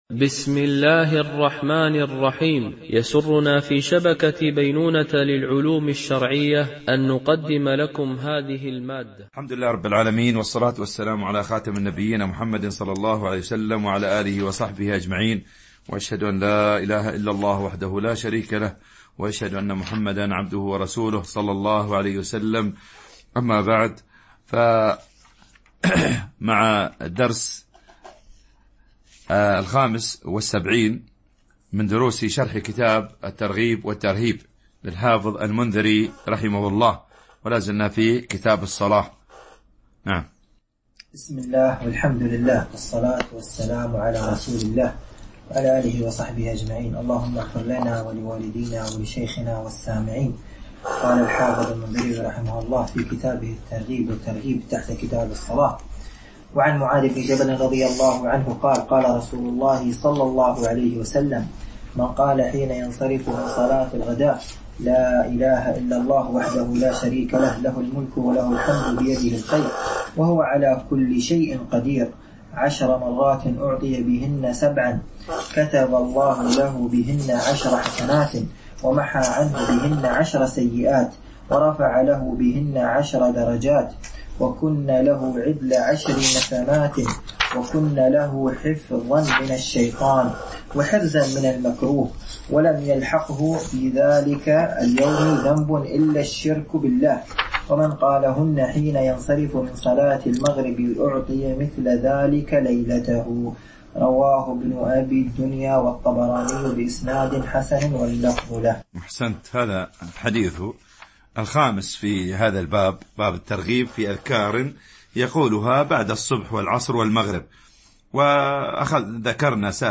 شرح كتاب الترغيب والترهيب - الدرس 75 ( كتاب الصلاة .الحديث 686 - 689)
MP3 Mono 22kHz 32Kbps (CBR)